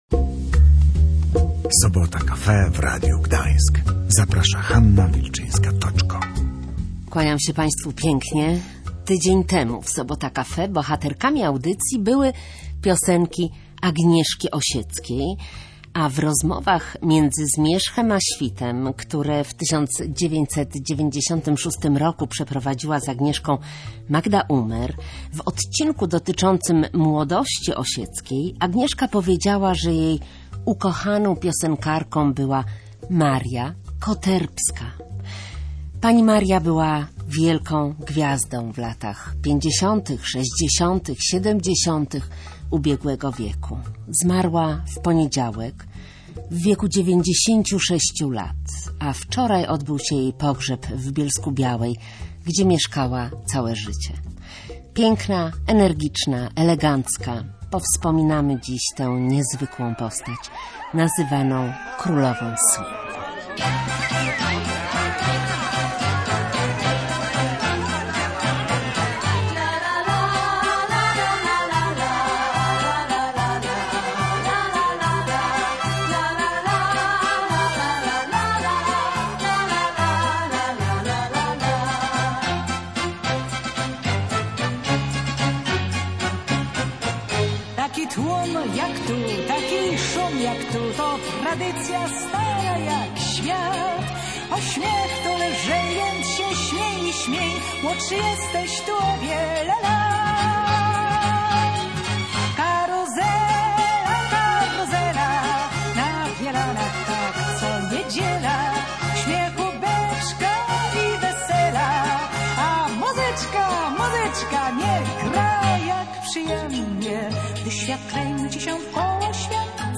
Zostawiła po sobie piękne piosenki, które w audycji Sobota Cafe przypomniała
Uznawana jest za pierwszą swingującą polską piosenkarkę.